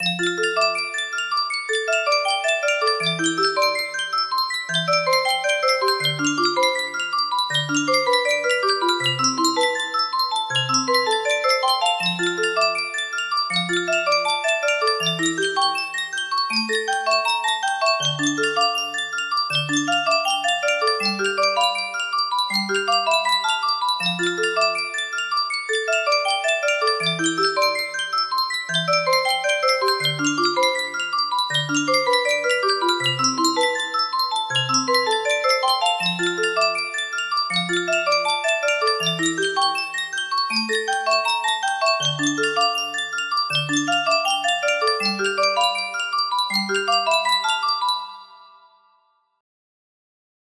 Full range 60
Transcribed by ear